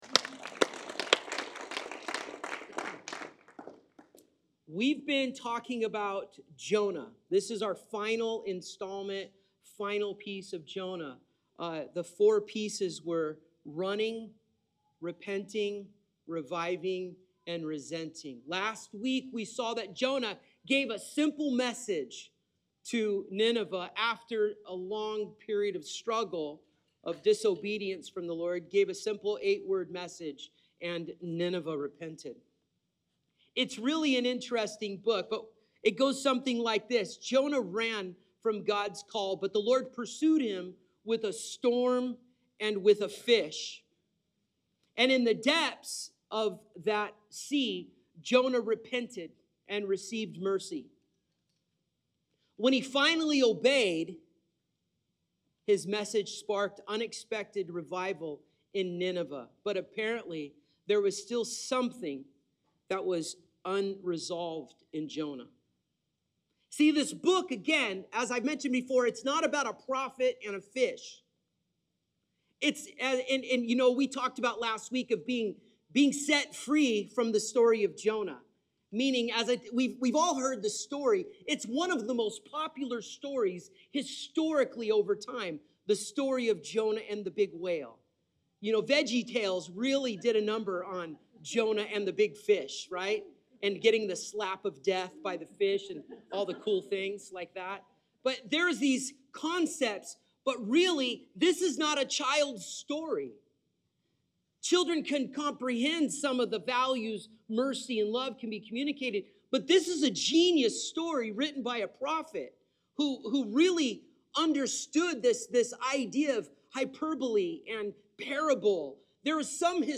Today, June 23rd, at LifeHouse Church we concluded of our series Swallowed by Mercy, where we dove deep into the story of Jonah!